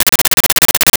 Arcade Movement 12.wav